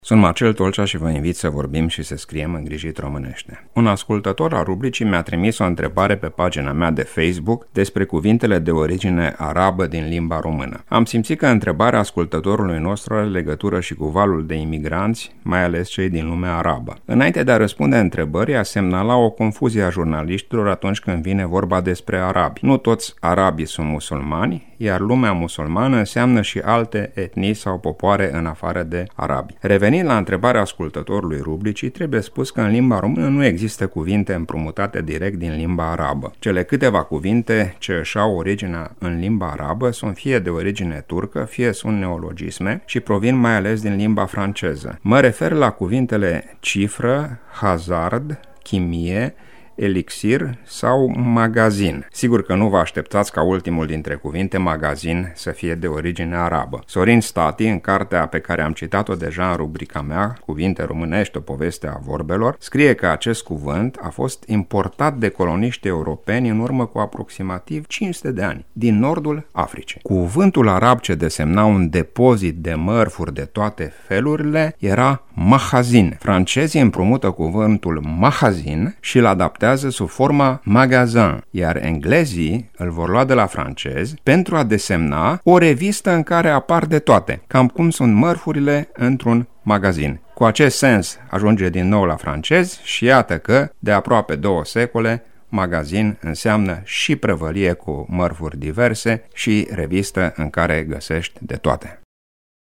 Rubricile sunt difuzate de luni până vineri inclusiv, în jurul orelor 7.40 şi 11.20 şi în reluare duminica, de la ora 8.00.